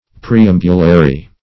Meaning of preambulary. preambulary synonyms, pronunciation, spelling and more from Free Dictionary.
Search Result for " preambulary" : The Collaborative International Dictionary of English v.0.48: Preambulary \Pre*am"bu*la*ry\, a. [Cf. OF. preambulaire.] Of or pertaining to a preamble; introductory; contained or provided for in a preamble.